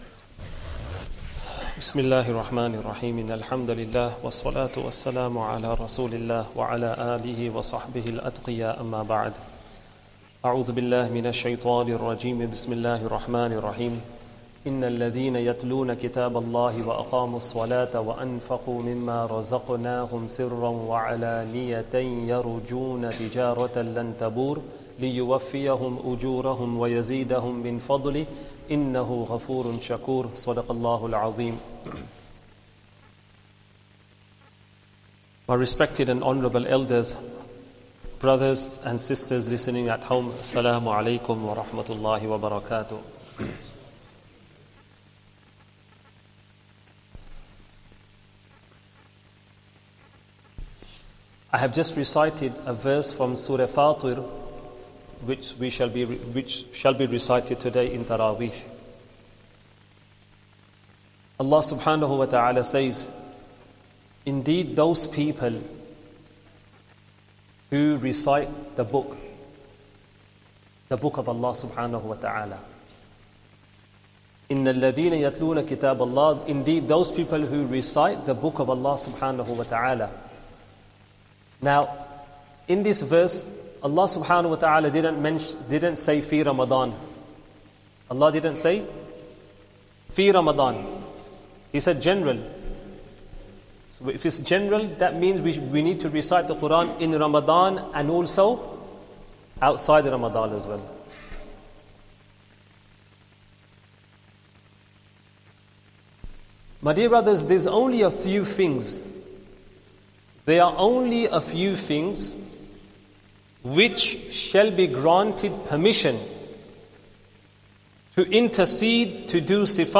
Esha Talk & Jammat